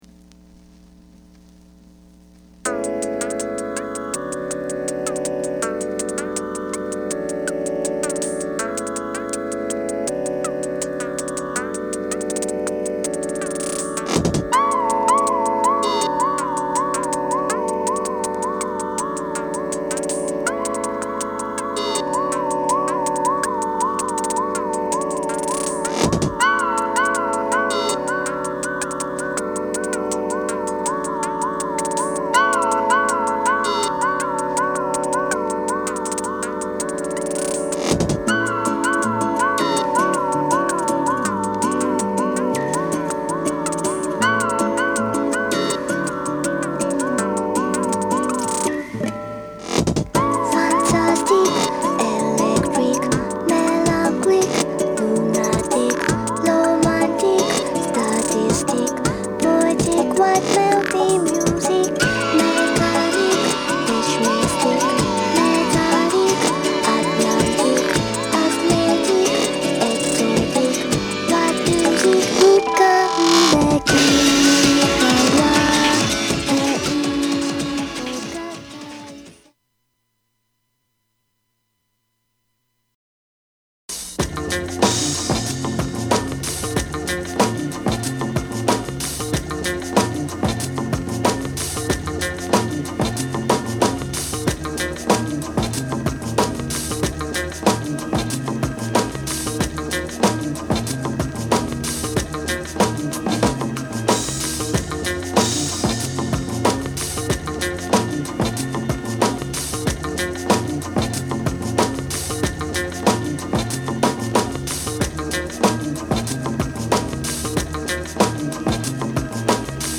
Electro, Acoustic, Synth-pop, Downtempo